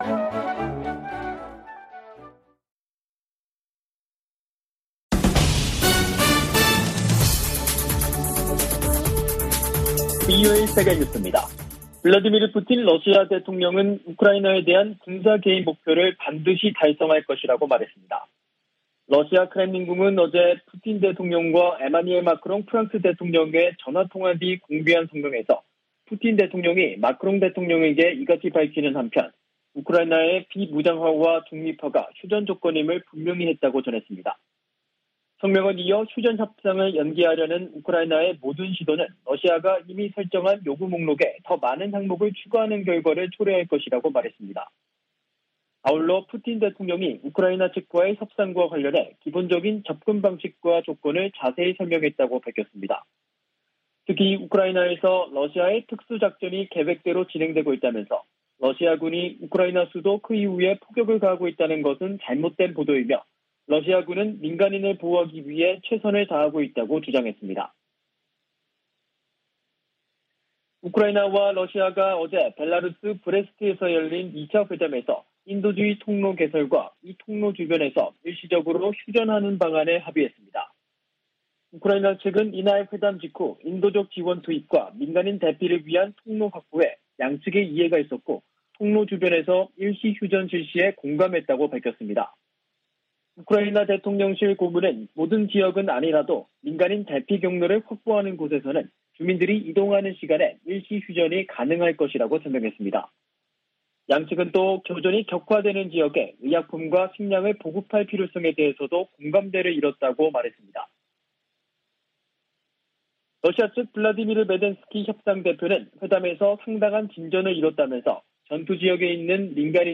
VOA 한국어 간판 뉴스 프로그램 '뉴스 투데이', 2022년 3월 4일 2부 방송입니다. 미 국무부는 러시아가 한국의 제재 동참에 유감을 표명한 데 대해, 국제사회가 무의미한 전쟁을 방어하는데 단결하고 있다고 강조했습니다. 북한이 우크라이나 사태에 러시아를 적극 두둔한 것은 핵 보유국 지위 확보를 노린 것이라는 분석이 나오고 있습니다. 미 상원의원들이 북한 등을 암호화폐 악용 국가로 지목하고 대책 마련을 촉구했습니다.